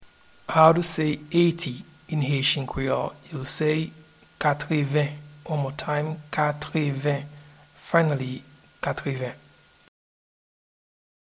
Eighty-in-Haitian-Creole-Katreven.mp3